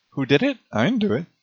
Sounds something like [ɐĭ.n̩.du.ɪt] (rough transcription, I’m not too good at transcribing from audio), and yet I can understand it just fine.